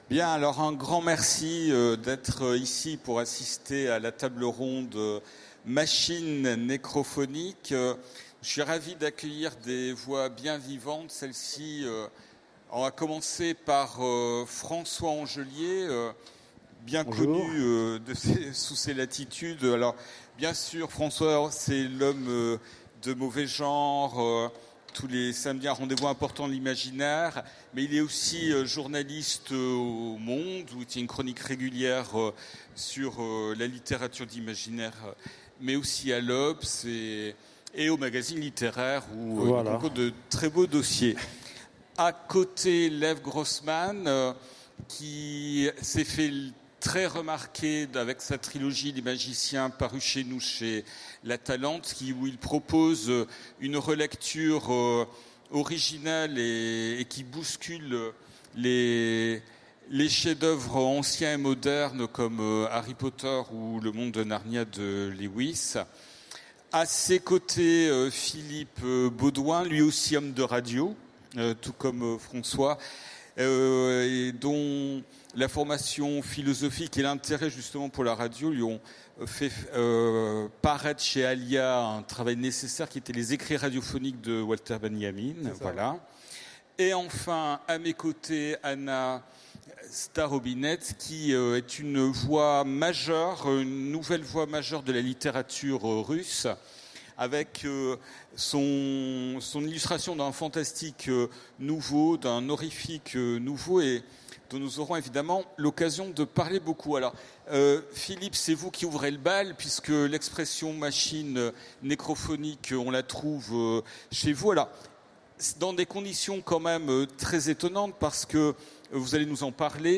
Utopiales 2016 : Conférence Les machines nécrophoniques